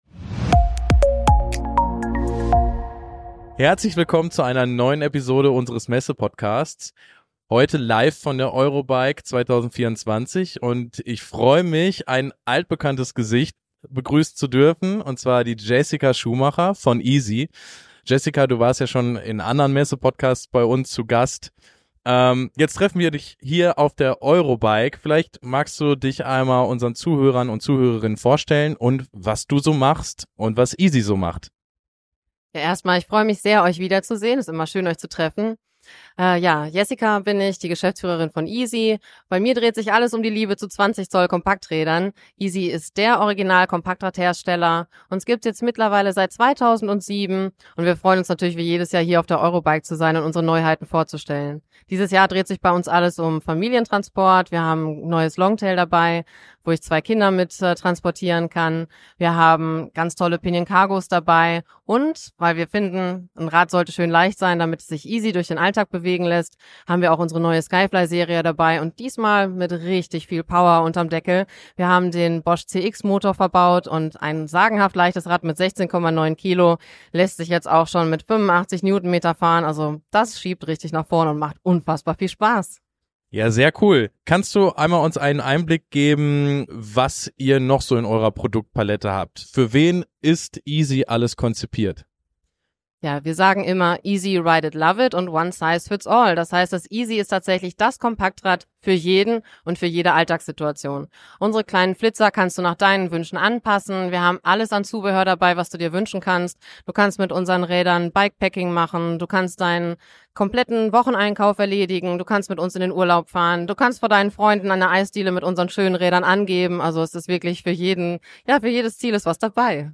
In dieser Episode des Messepodcasts sprechen wir live von der von